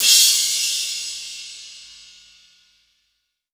• Long Room Reverb Crash One Shot C# Key 03.wav
Royality free crash cymbal sound sample tuned to the C# note. Loudest frequency: 5859Hz
long-room-reverb-crash-one-shot-c-sharp-key-03-CoW.wav